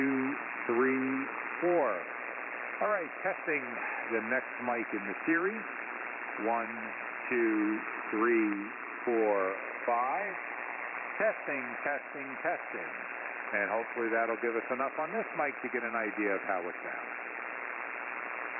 All of the tests were conducted in the HF bands including 80, 40 and 10 meters.
• The Shure sounded better for communication
• Feels brighter, just sounds better overall
Shure SM58
Shure-SM58.mp3